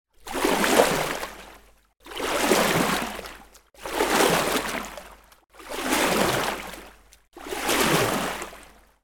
На этой странице собраны звуки весла: плеск воды, ритмичные гребки и другие умиротворяющие аудиоэффекты.
Медленно гребем